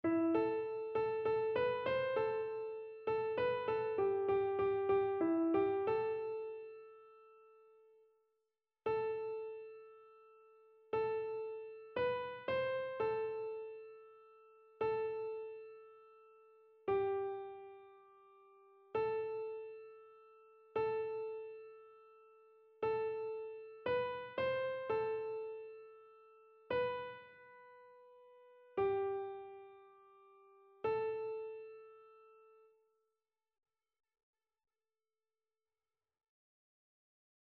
Chœur